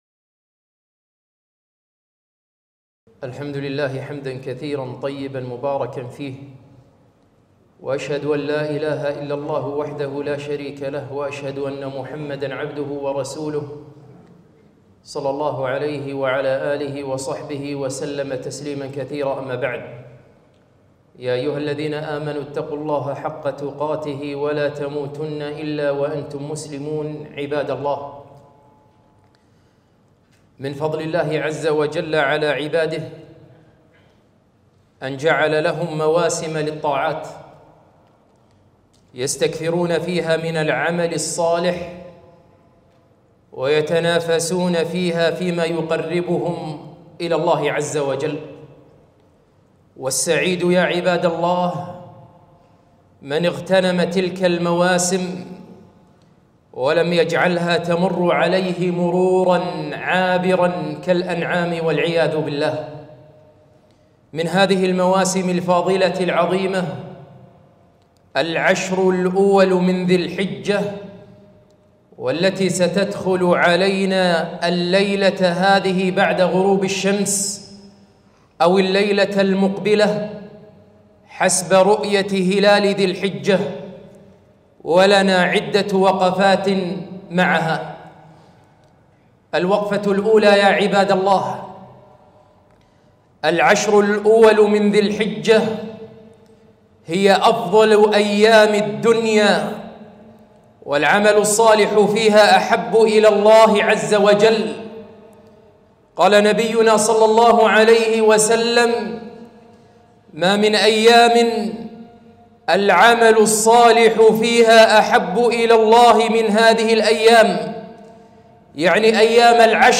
خطبة - اغتنام العشر